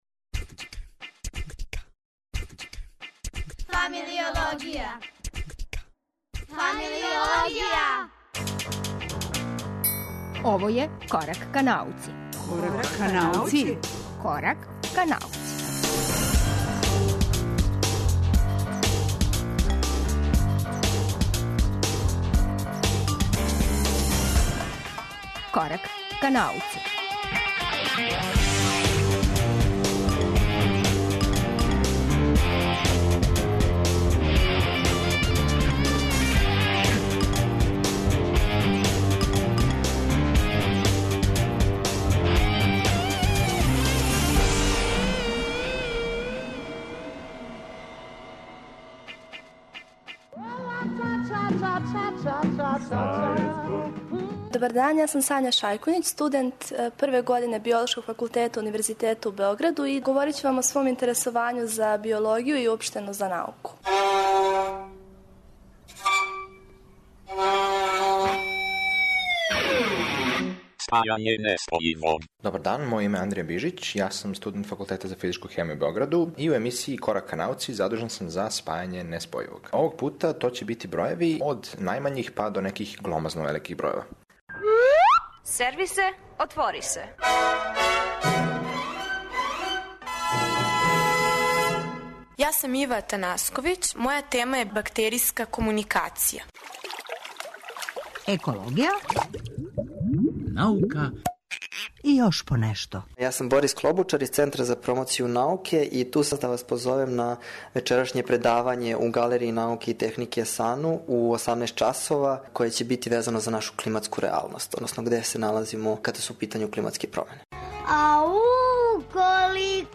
Емисију и овог четвртка чини пет разговора - о првим корацима ка биологији, бактеријској комуникацији, тешко појмљивим распонима на скали од микро до макро, климатским променама и књигама које се баве квантним светом - и шест немогућих ствари - о случајним открићима.